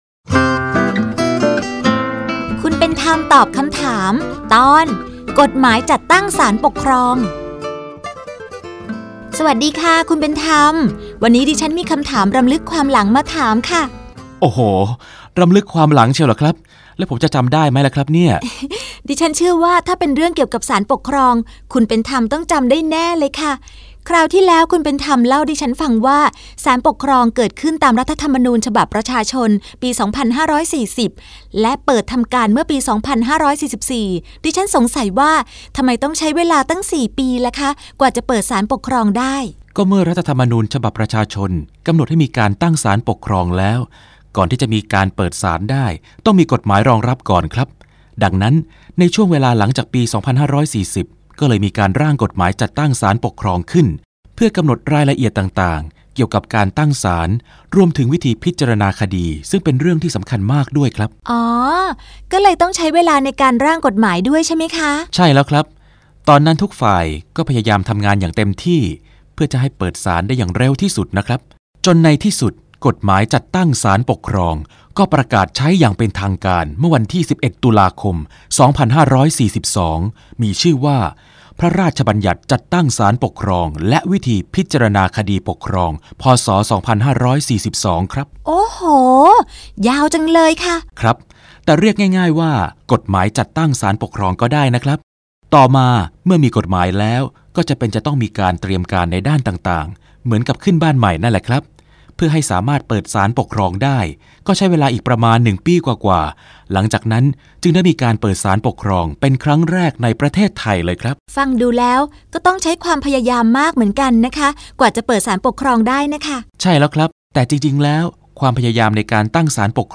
สารคดีวิทยุ ชุดคุณเป็นธรรมตอบคำถาม ตอน กฎหมายจัดตั้งศาลปกครอง